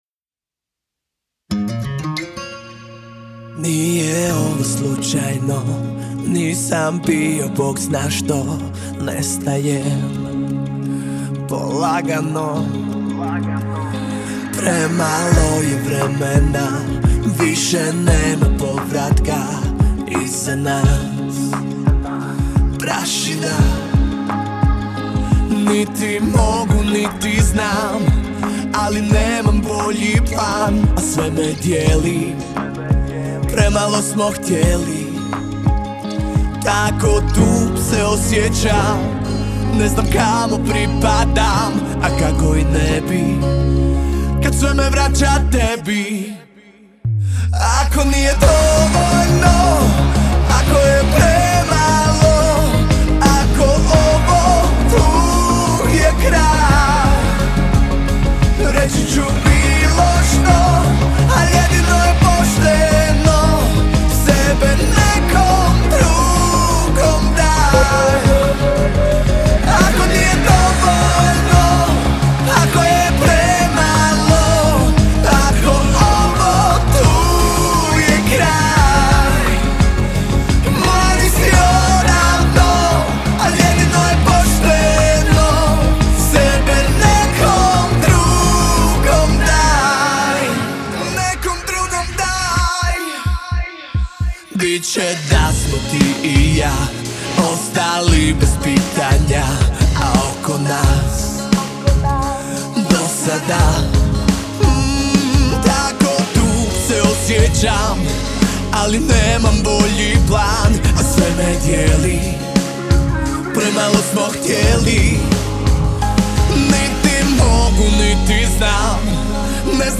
Žanr Pop